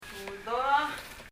« poison 毒 rubber ゴム » grape 葡萄（ブドウ） budo [budo] 「葡萄」です。